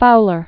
(foulər), Henry Watson 1858-1933.